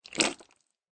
squelch.ogg